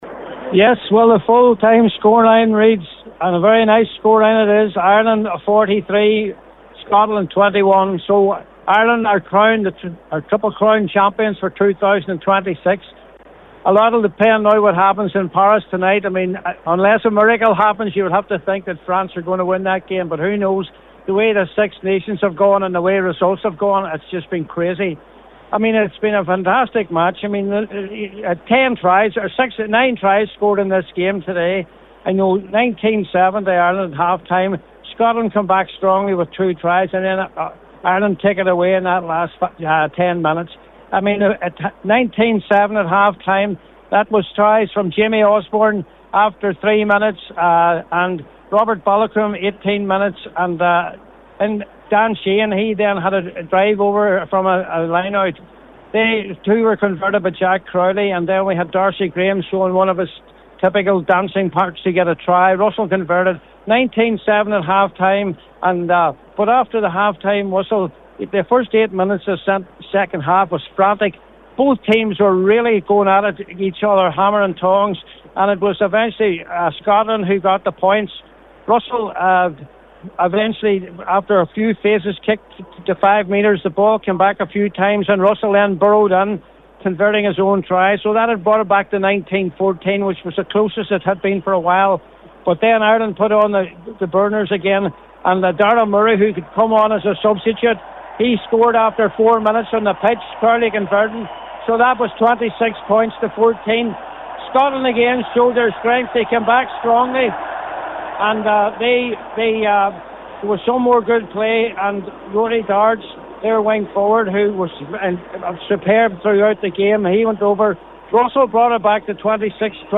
was live at full time for Highland Radio Sport…